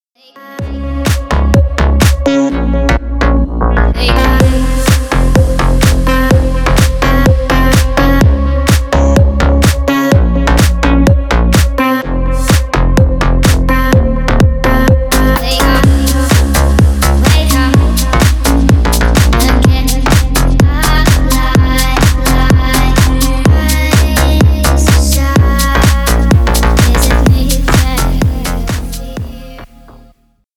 Танцевальные
громкие # клубные